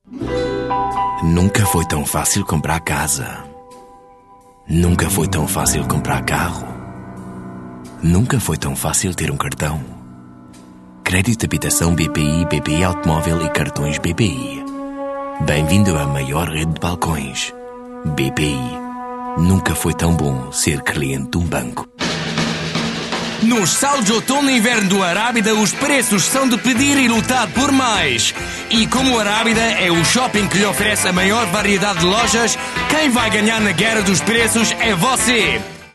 Portuguese, Male, 20s-30s